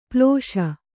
♪ plōṣa